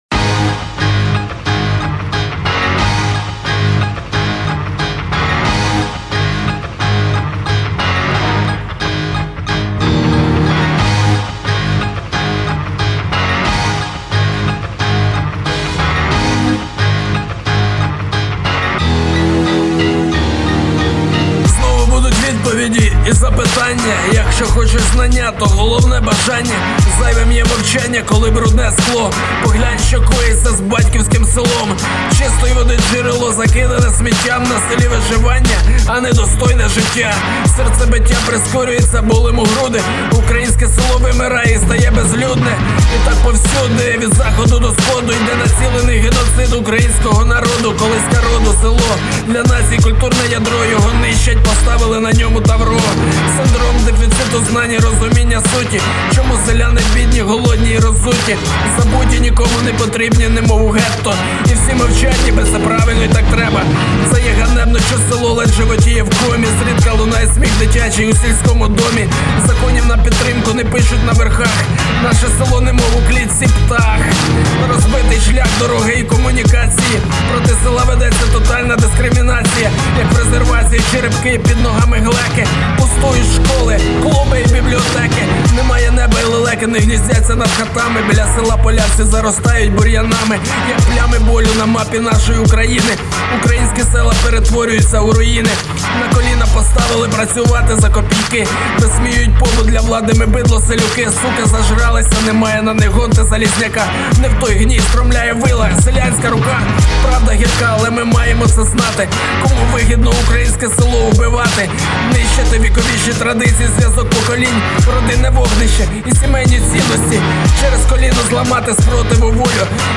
пісня, реп, кому неважко може написати про слабкі сторони, буду вдячний.
Чудово виконано!